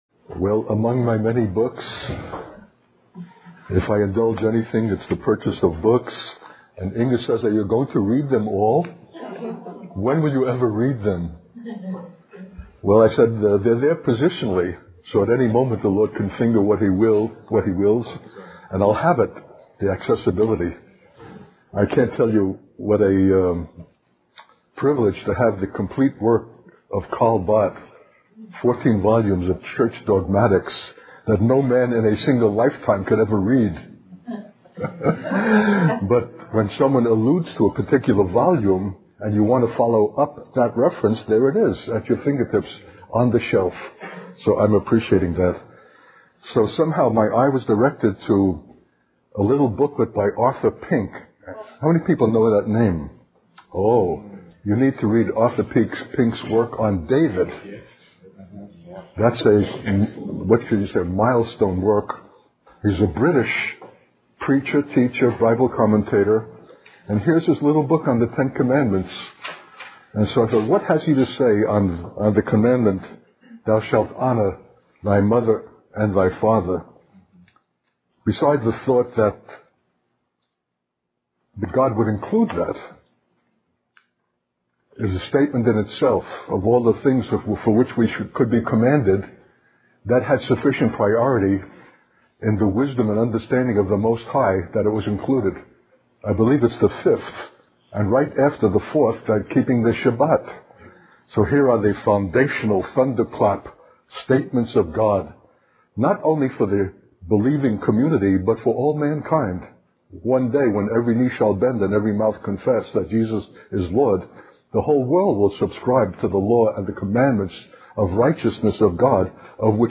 How we relate to any form of authority is the greatest opportunity to demonstrate the grace and knowledge of God, and is the very sanctifying provision of God itself. A 2003 message.